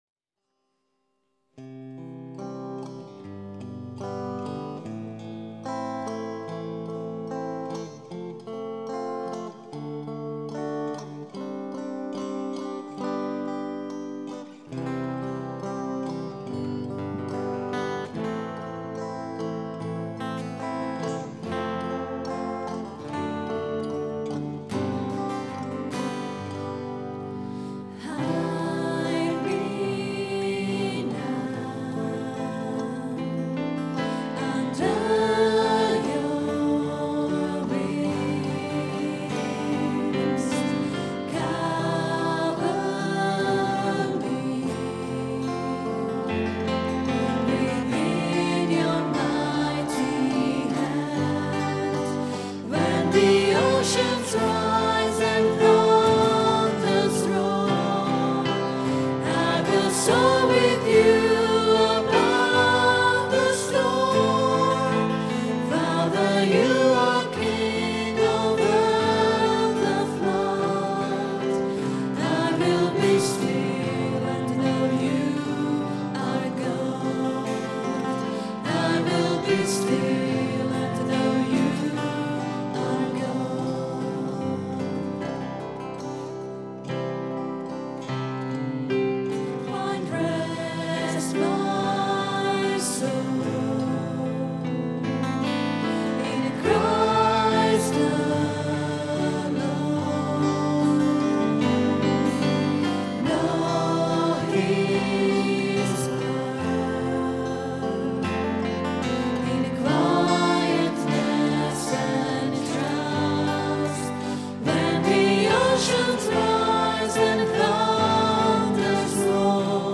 Recorded on a Zoom H4 digital stereo recorder at 10am Mass Sunday 9th November, 2008.